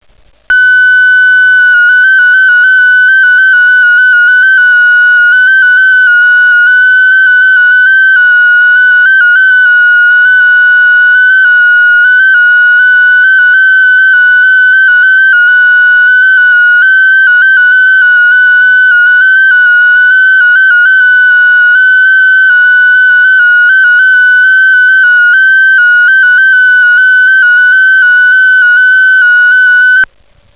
Espectro del modo Ros 7/100.
Escuche como suena un CQ en este modo: